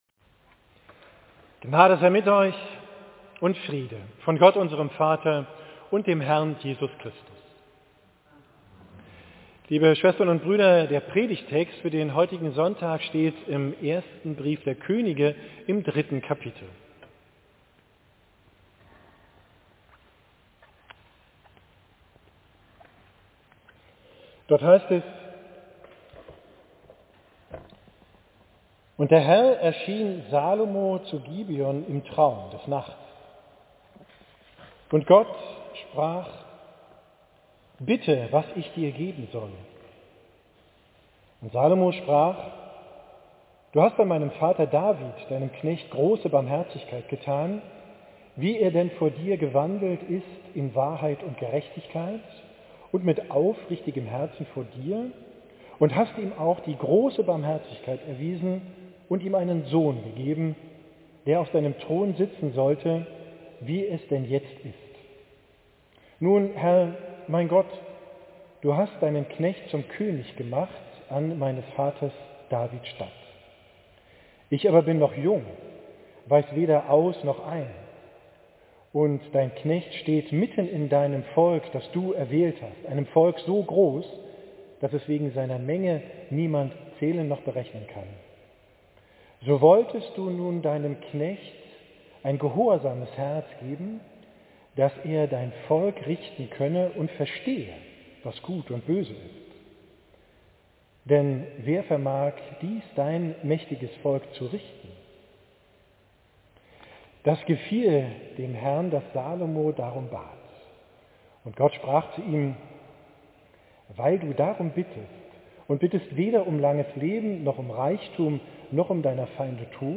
Predigt vom 9.